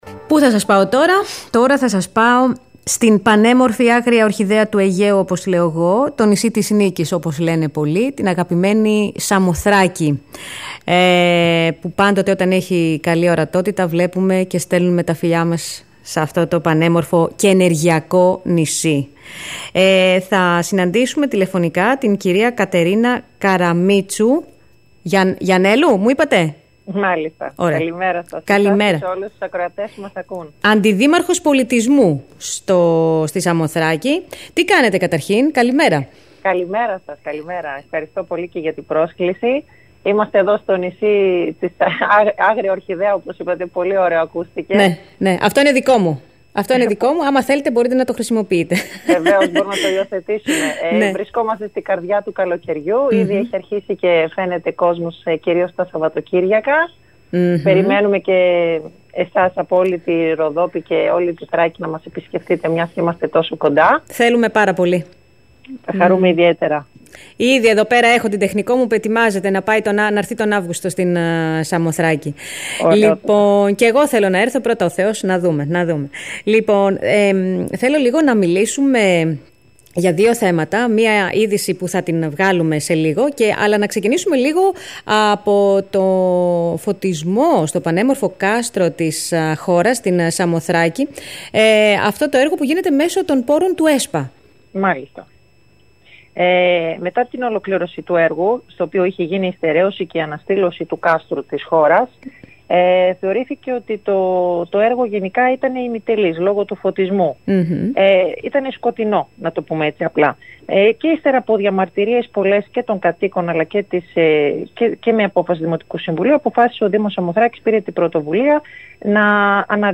Αυτό τόνισε μιλώντας στην ΕΡΤ η αντιδήμαρχος Πολιτισμού, Παιδείας και Αθλητισμού, Κατερίνα Καραμήτσου-Γιαννέλου, μετά από τις συζητήσεις που προηγήθηκαν με το Σύλλογο Ομογενών του Εξωτερικού.